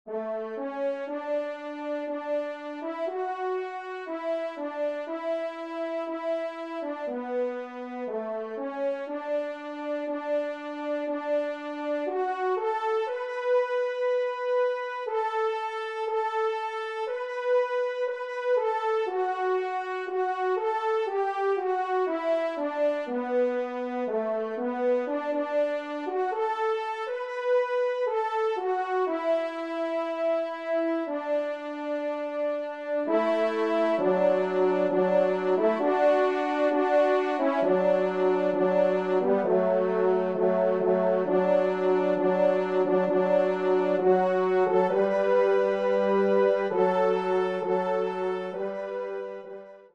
Auteur : Chant Traditionnel Écossais
2e Trompe